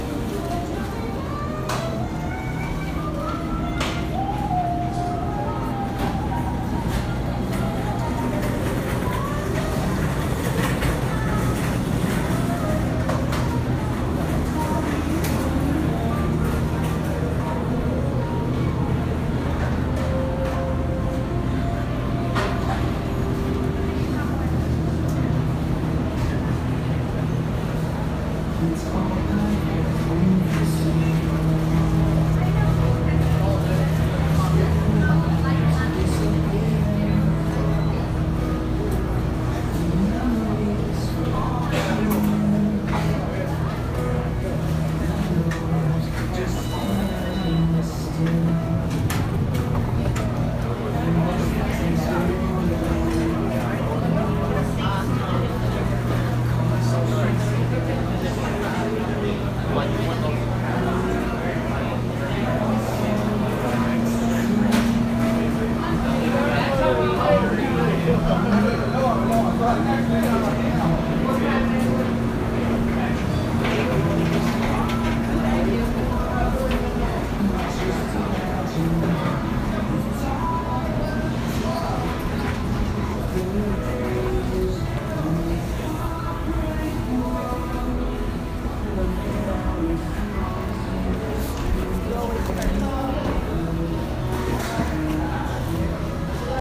GRAND CENTRAL STATION MARKET, NEW YORK
grand-central-station-market.m4a